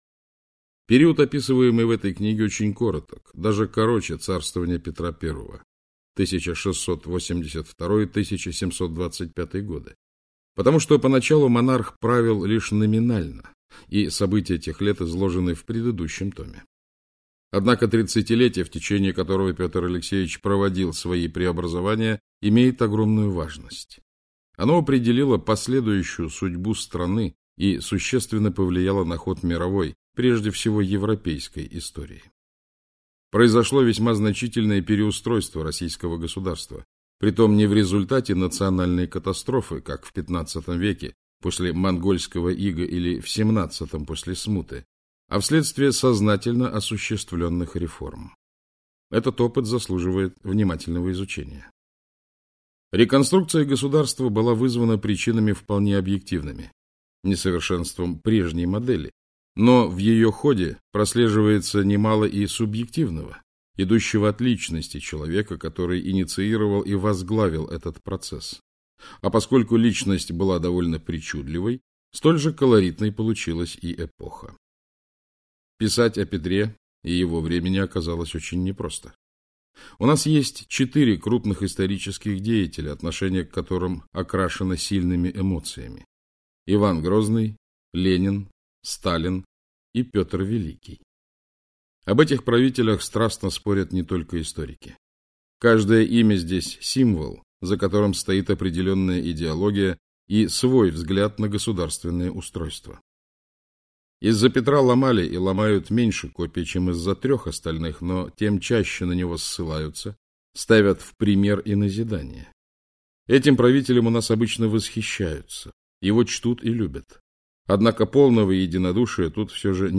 Аудиокнига Азиатская европеизация. История Российского государства. Царь Петр Алексеевич - купить, скачать и слушать онлайн | КнигоПоиск